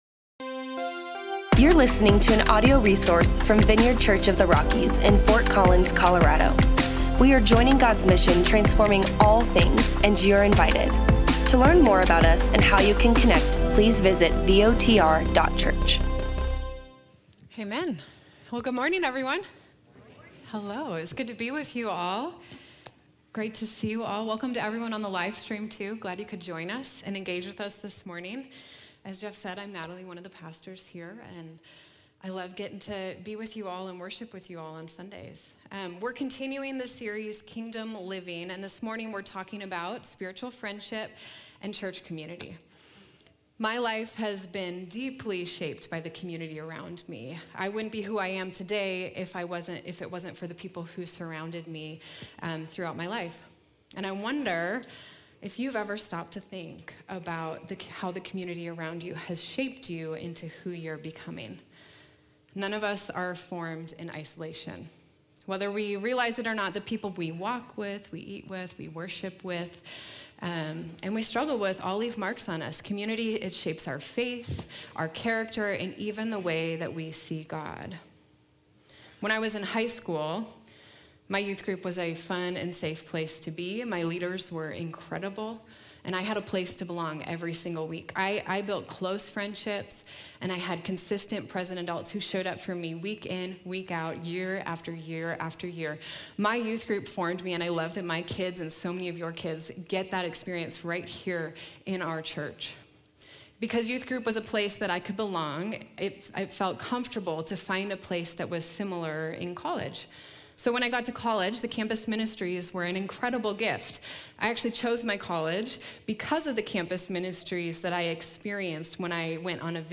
Continue the Kingdom Living series, listen in as we explore the picture of the early church in Acts 2 and discover how shared life, joyful generosity, worship, and mission draw us closer to Jesus and one another. If you’re longing for deeper connection, meaningful community, and a faith that’s lived out—not just believed—this sermon will invite you into the kind of life Jesus always intended for His people.